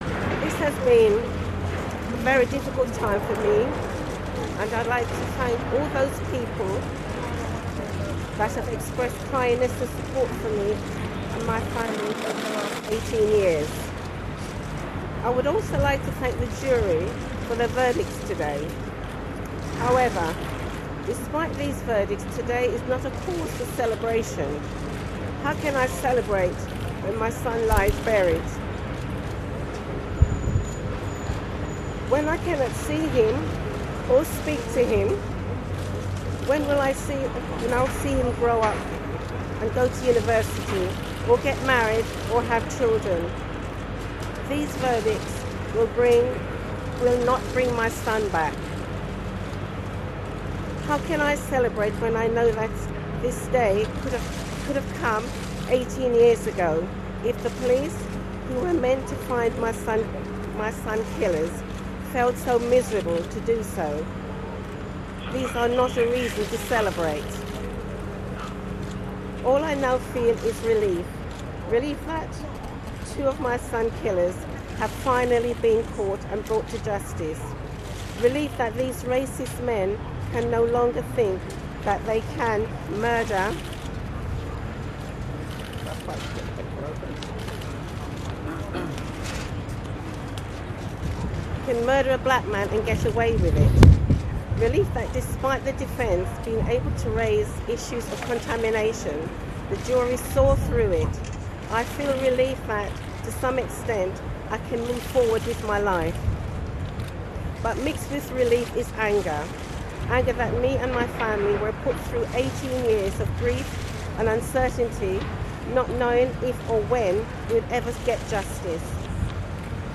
"No cause for celebration": The mother of Stephen Lawrence on the convictions of two men for murder